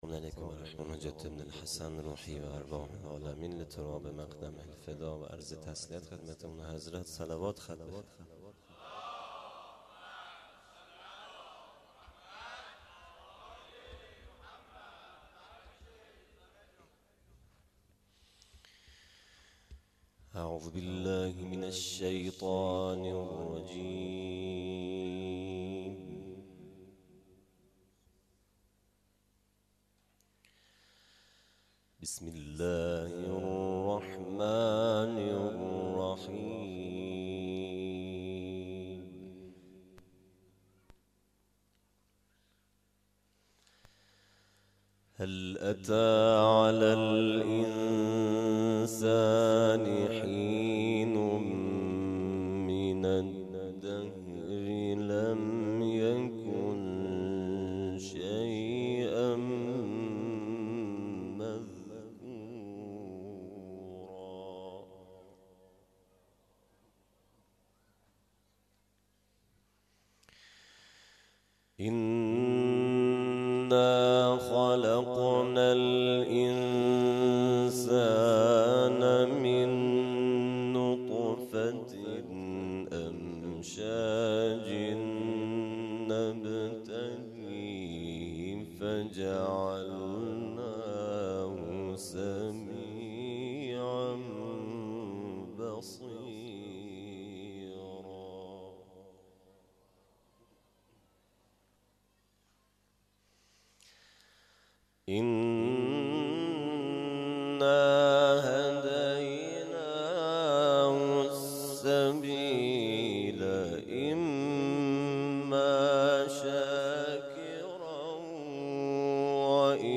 اقامه عزای فاطمیه جامعه ایمانی مشعر